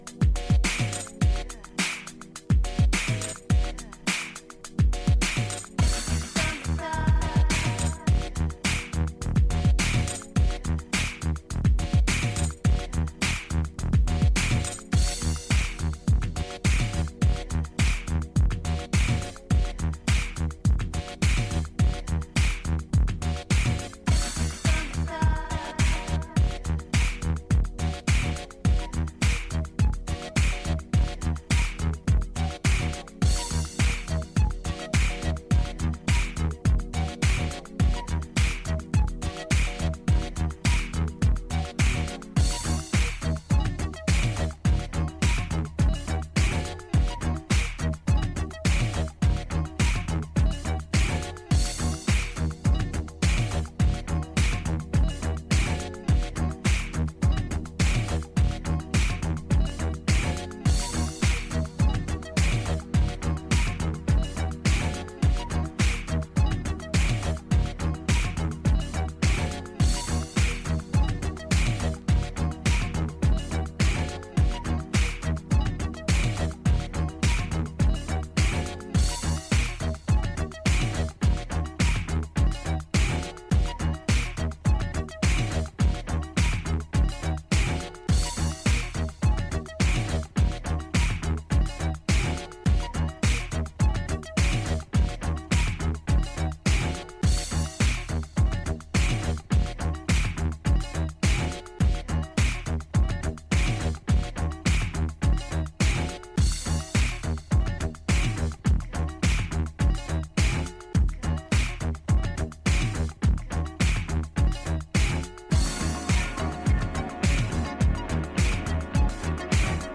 ここでは、シンセ・ポップやアフロ・トロピカル路線の音源をネタに用いながらゆるくてダビーなバレアリック・トラックを展開。